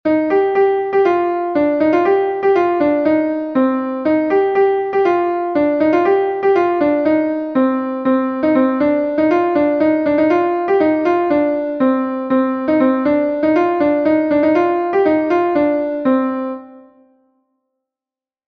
Gavotenn Ignol est un Gavotte de Bretagne